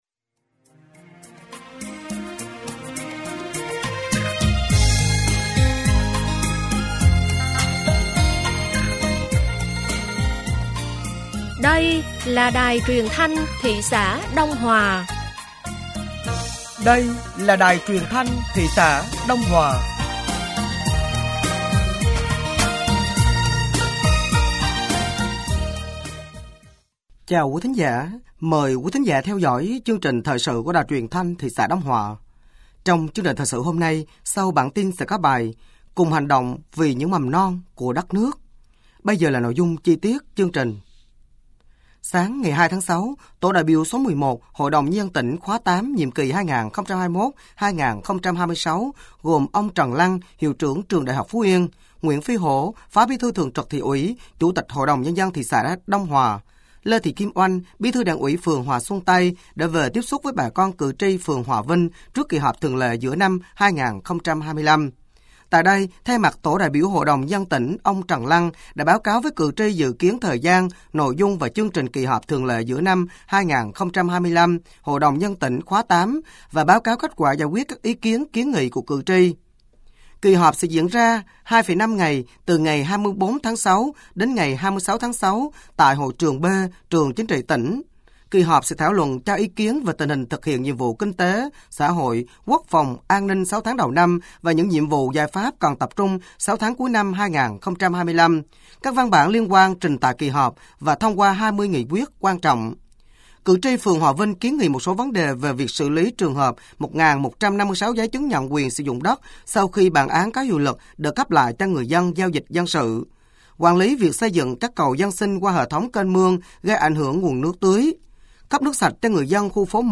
Thời sự tối ngày 2/6 sáng ngày 3/6/2025